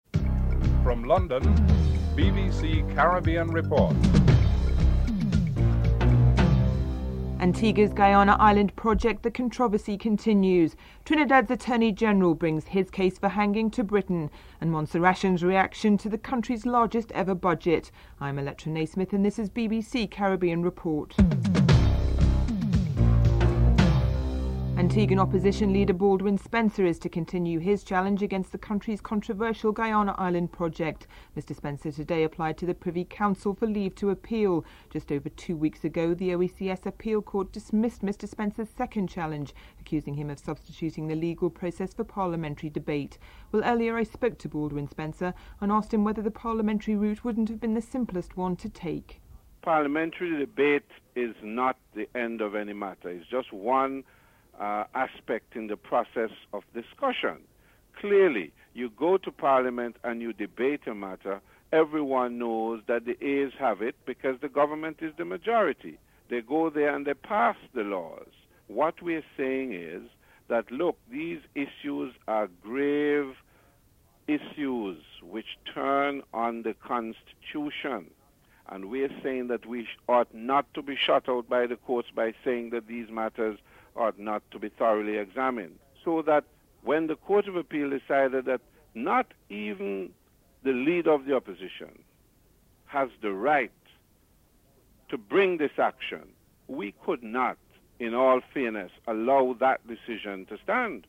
2. Antigua's Opposition Leader Baldwin Spencer is to continue his challenge against the controversial Guiana Island project. Spencer comments on whether the parliamentary route would have been the simpler one to take (00:24-02:51)